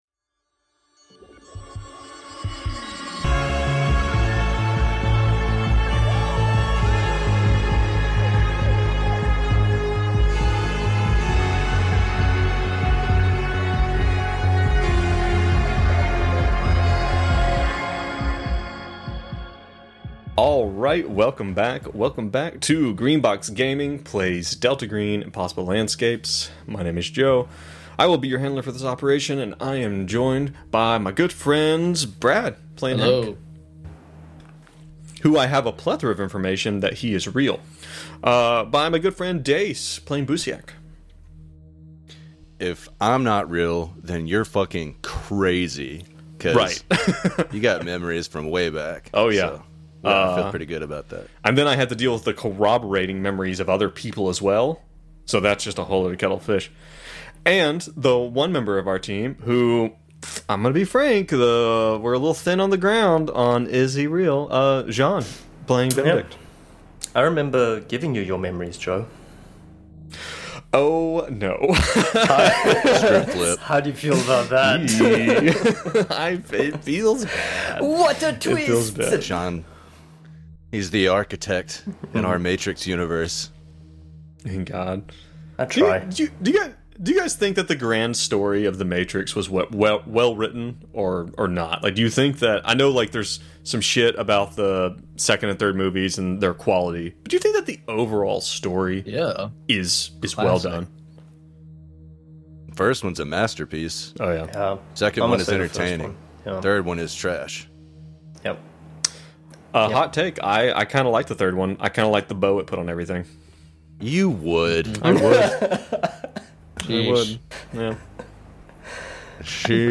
Four friends launch into a game of horror, mystery, and conspiracy where regular people fight against the unknown at the expense of their relationships, sanity, and lives. We are playing Delta Green: Impossible Landscapes!